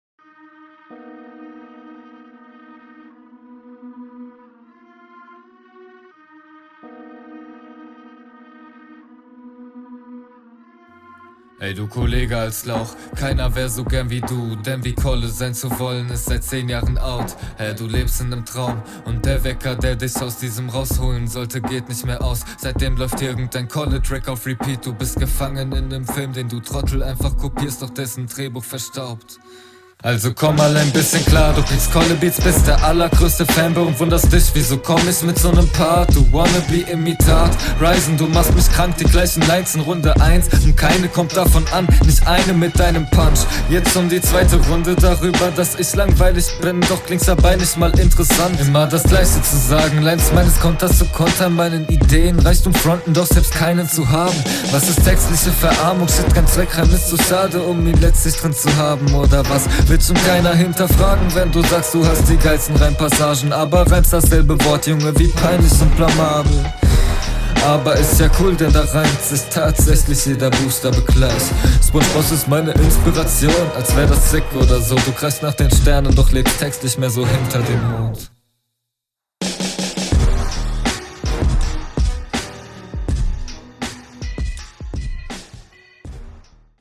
Stimmlich passt auch du dich gut dem Beataufbau an!